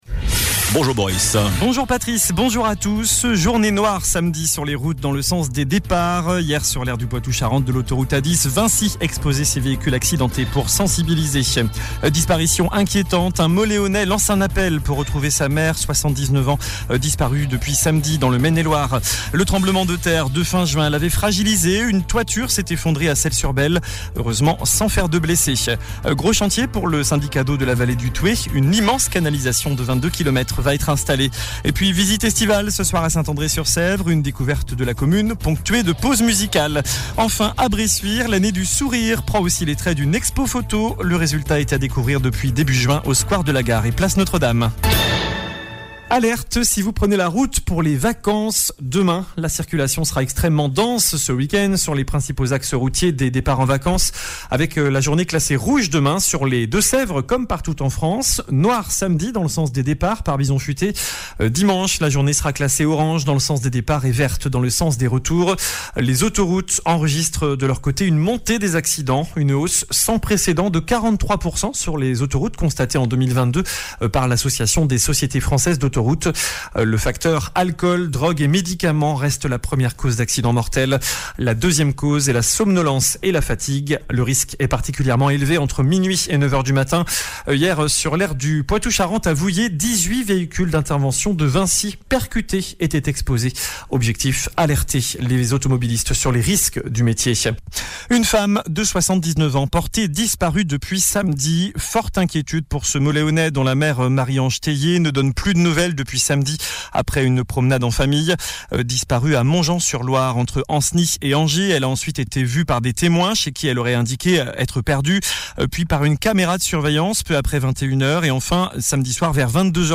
JOURNAL DU JEUDI 03 AOÛT ( MIDI )